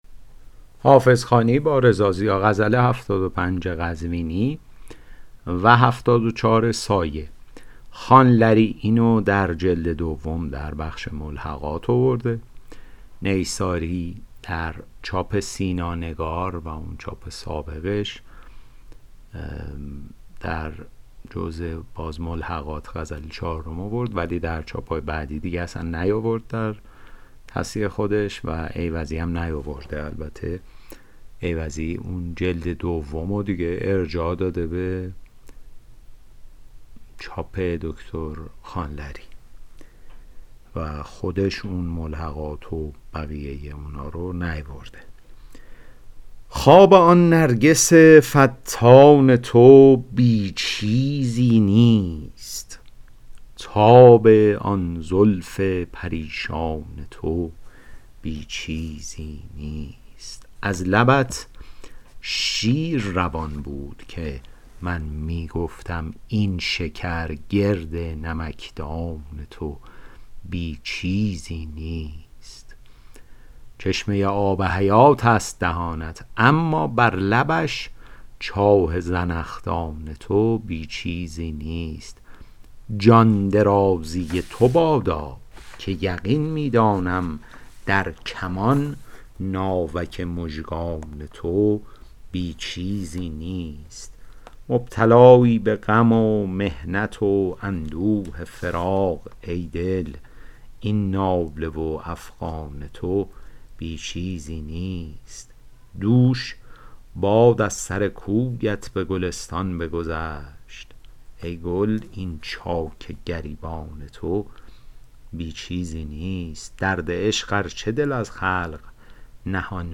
شرح صوتی غزل شمارهٔ ۷۵